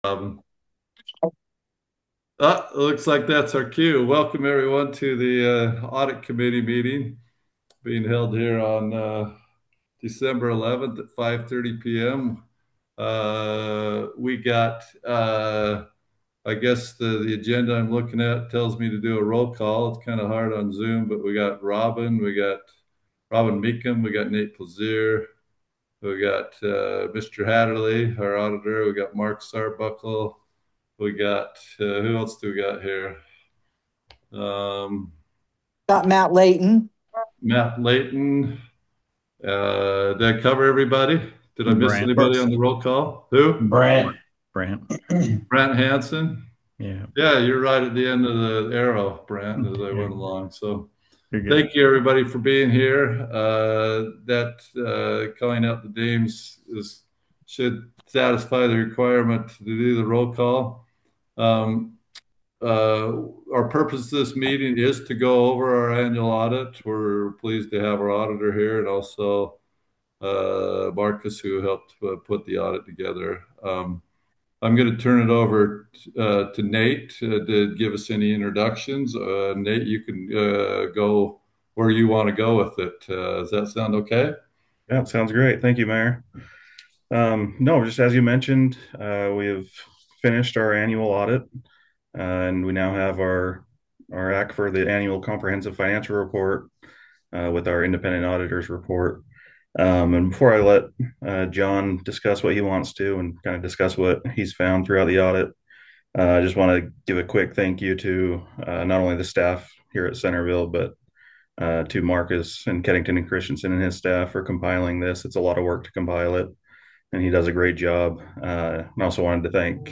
NOTICE IS HEREBY GIVEN THAT THE CENTERVILLE AUDIT COMMITTEE WILL HOLD A REGULAR MEETING AT 5:30 PM ON DECEMBER 11, 2025 AT ELECTRONICALLY VIA ZOOM.